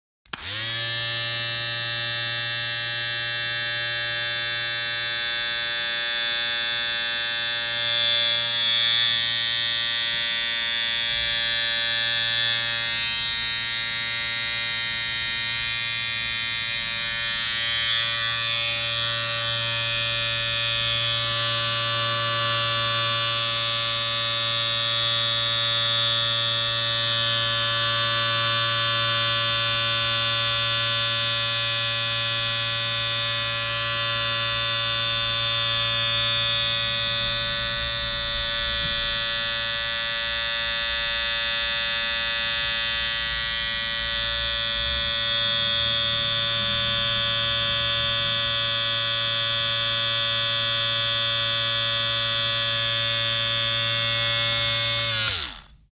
Razor
Tags: haircuts